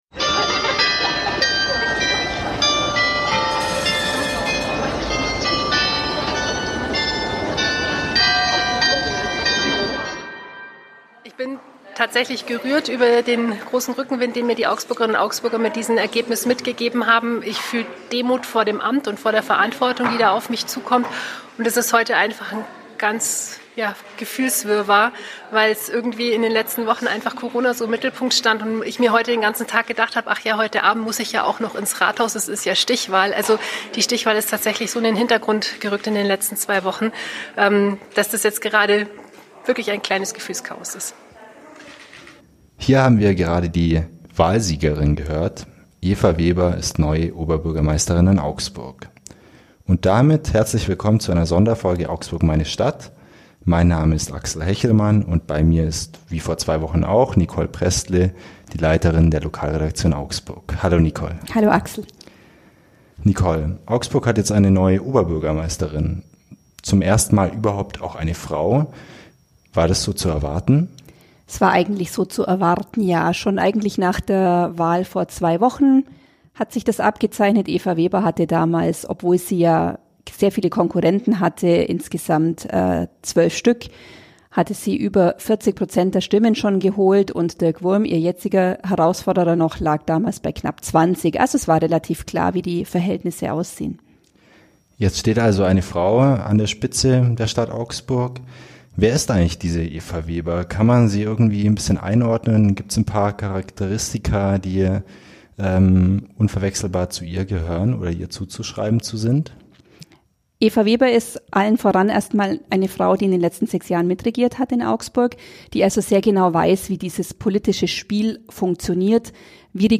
In einer Sonderfolge unseres Podcasts "Augsburg, meine Stadt" analysieren wir den Ausgang der Kommunalwahl und ihre Folgen.